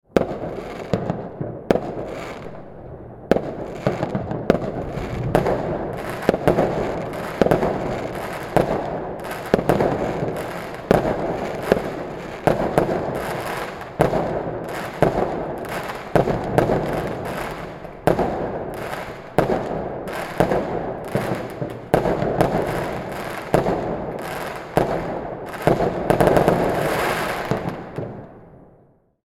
Celebration Firework Explosions Sound Effect
Street New Year’s Eve fireworks at midnight with loud explosions, urban echo between buildings, and a festive celebration atmosphere.
Celebration-firework-explosions-sound-effect.mp3